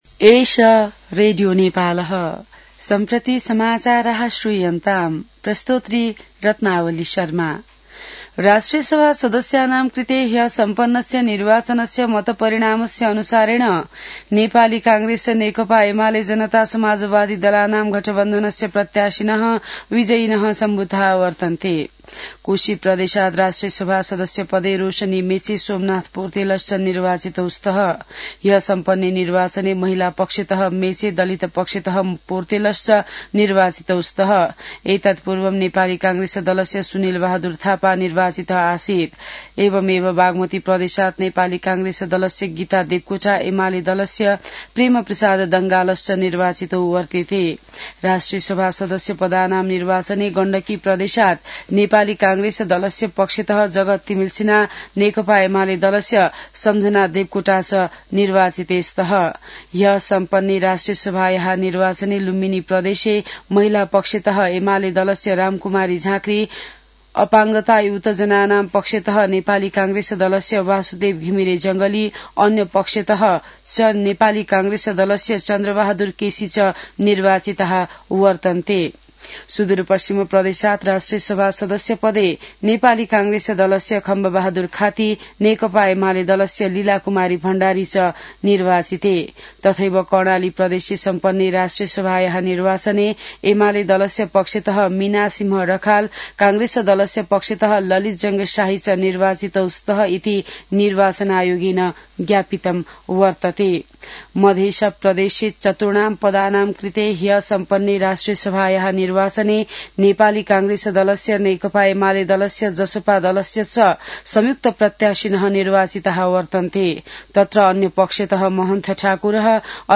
An online outlet of Nepal's national radio broadcaster
संस्कृत समाचार : १२ माघ , २०८२